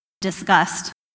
Can you differentiate the words discussed and disgust as produced by native speakers in natural sentences?
discussed or disgust? (USA)